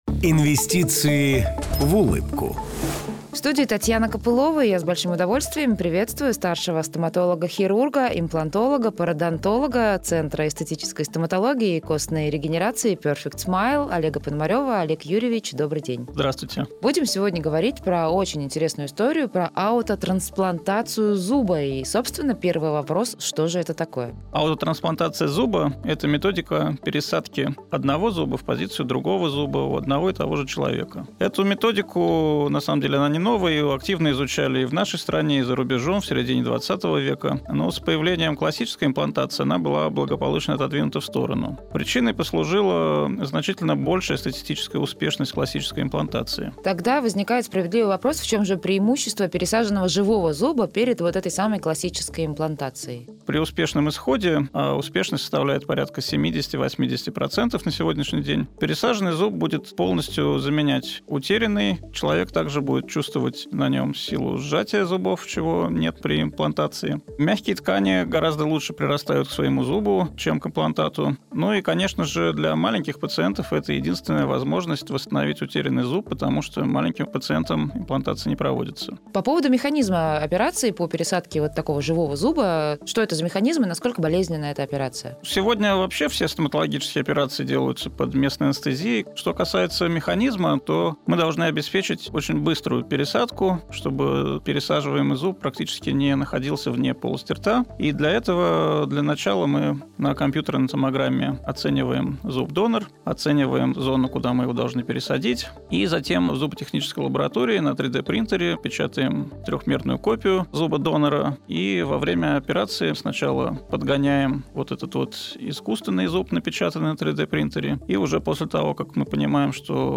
беседует со старшим стоматологом-хирургом, имплантологом и парадонтологом